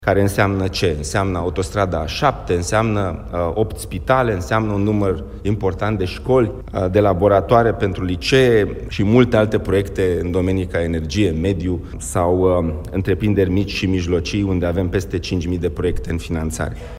Până acum a fost haos, timp de cinci ani proiectele s-au gestionat în totală dezordine, spune ministrul Proiectelor Europene, Dragoș Pîslaru, într-o dezbatere a platformei „Curs de guvernare”: